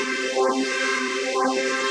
SaS_MovingPad03_125-C.wav